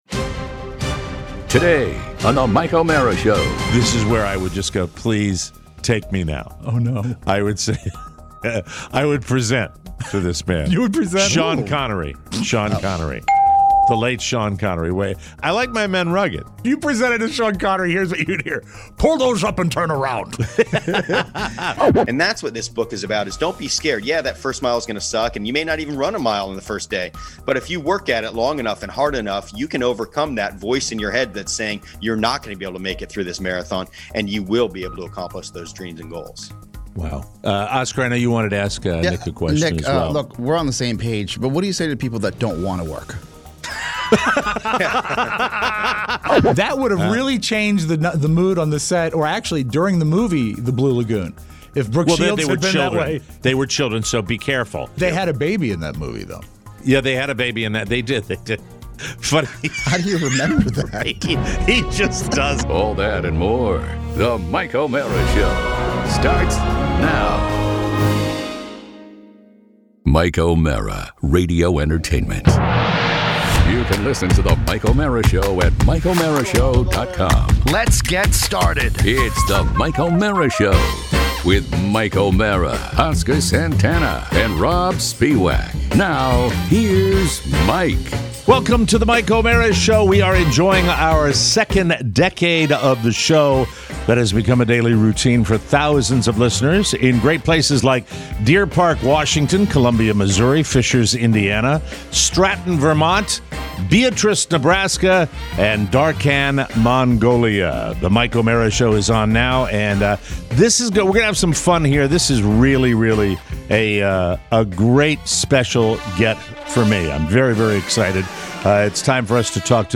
Today we're joined by acrobat Nik Wallenda to discuss his new book, Facing Fear!